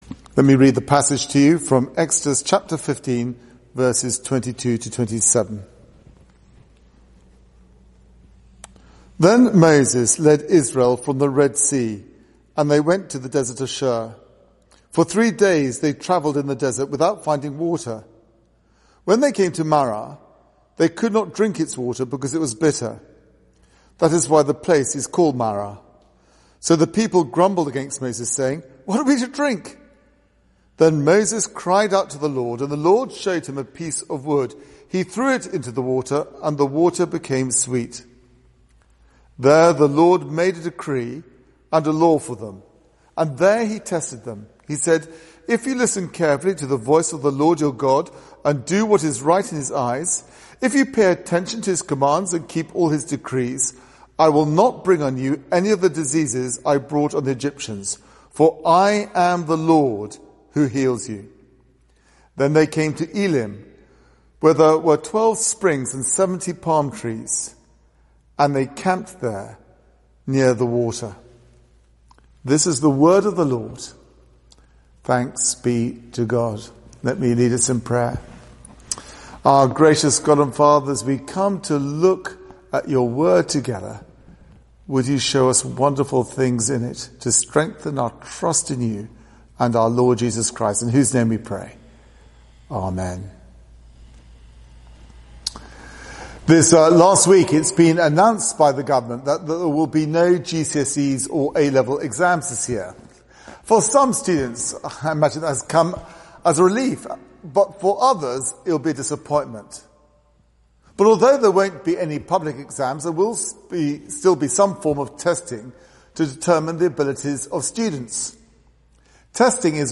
Media for 9:15am Service on Sun 10th Jan 2021 09:15 Speaker
Sermon (Audio)